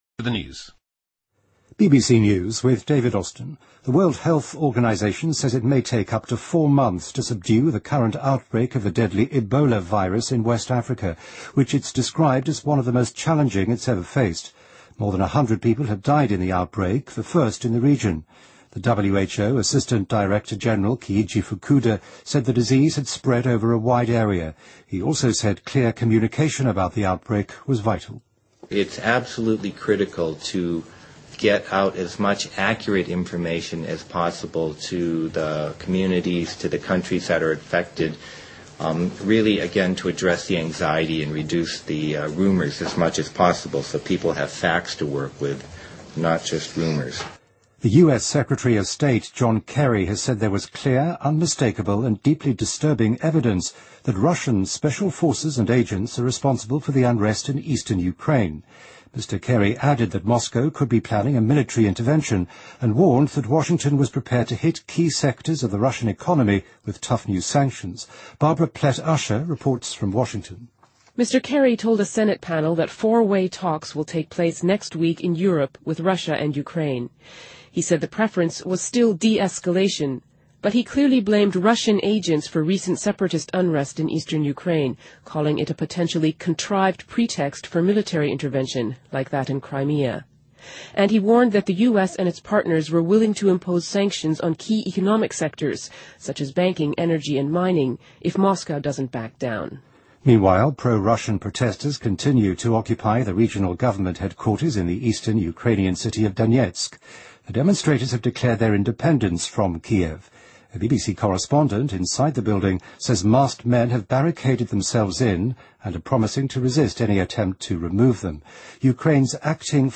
BBC news,2014-04-09